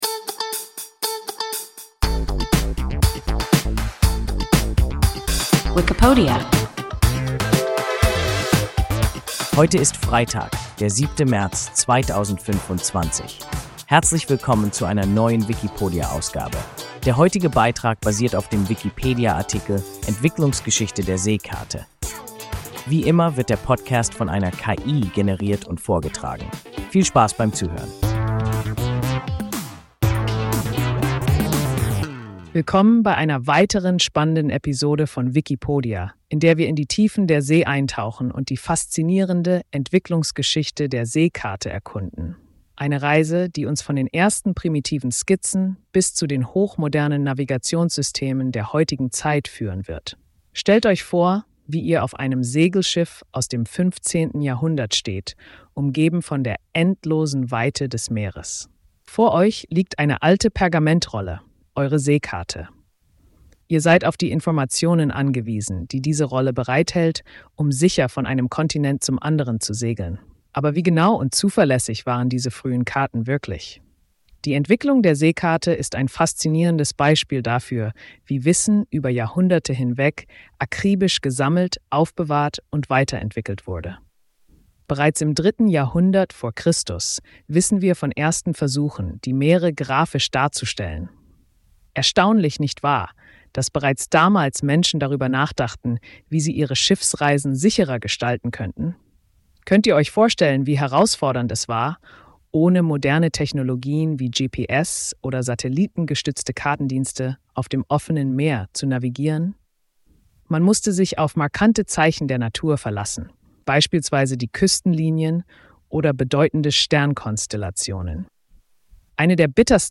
Entwicklungsgeschichte der Seekarte – WIKIPODIA – ein KI Podcast